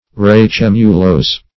Search Result for " racemulose" : The Collaborative International Dictionary of English v.0.48: Racemulose \Ra*cem"u*lose`\ (r[.a]*s[e^]m"[-u]*l[=o]s`), a. (Bot.)